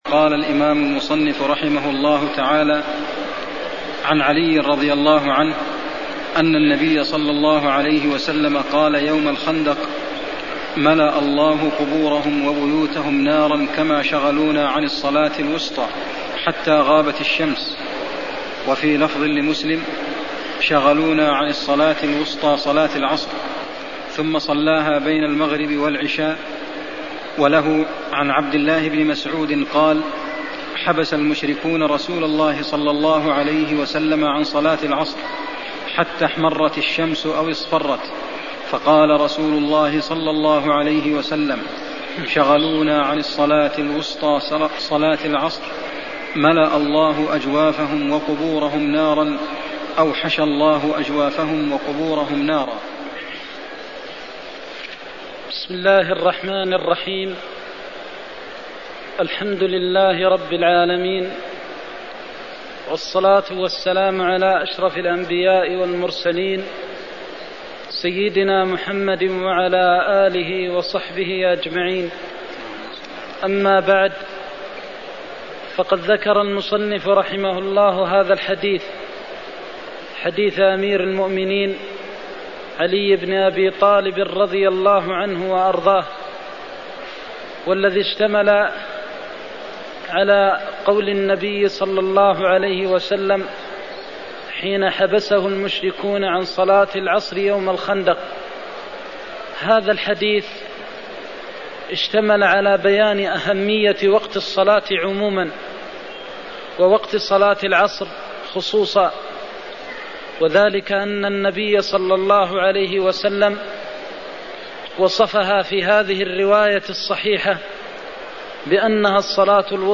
المكان: المسجد النبوي الشيخ: فضيلة الشيخ د. محمد بن محمد المختار فضيلة الشيخ د. محمد بن محمد المختار شغلونا عن الصلاة الوسطى (48)